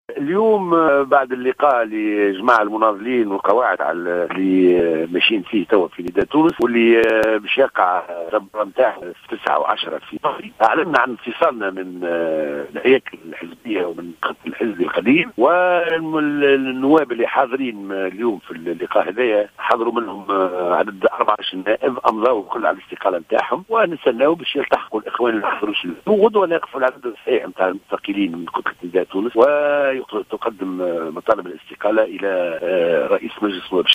أكد النائب عن حركة نداء تونس وعضو مجموعة 32 عبادة الكافي في تصريح للجوهرة "اف ام" أن أعضاء المجموعة بصدد جمع الإمضاءات من أجل تقديم استقالتهم رسميا يوم غد الإثنين مشيرا إلى أن 14 نائبا وقعوا على قرار الإستقالة من الكتلة إلى حد اللحظة.